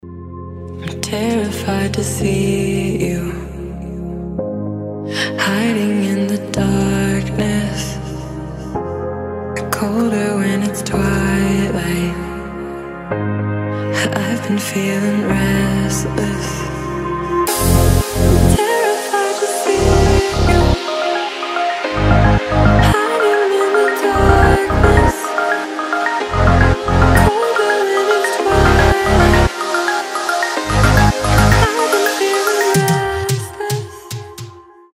• Качество: 320, Stereo
мелодичные
Electronic
нарастающие
progressive house
красивый женский голос